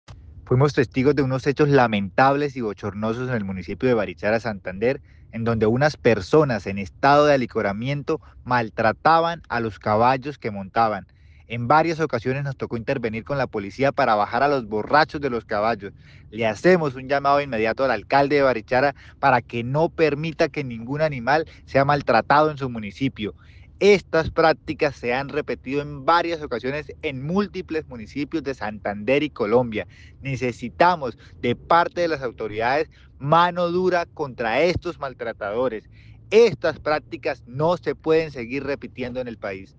Danovis Lozano, diputado de Santander
En vídeo quedó registrado el momento en el que el diputado por el partido verde denunció a las personas que montaban los caballos, además en ese registro se escuchó a un policía decir que una de estas personas lo maltrató.